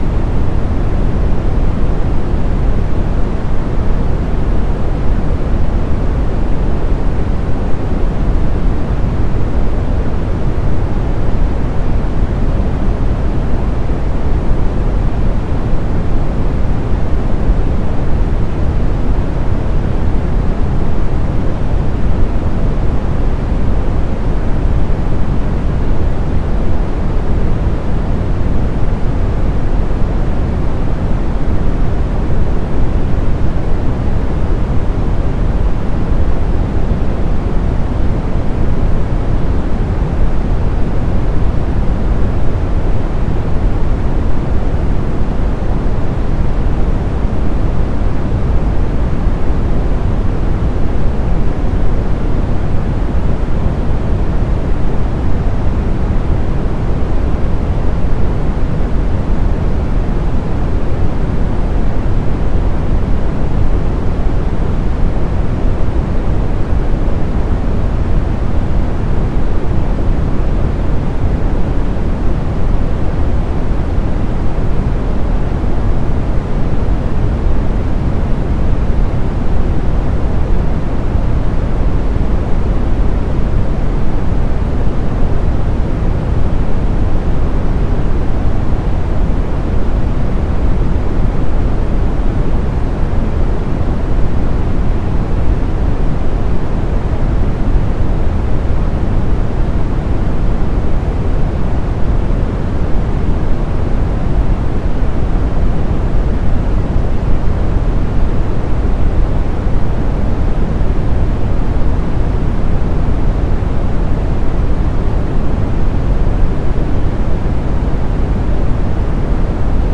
BrownNoise.mp3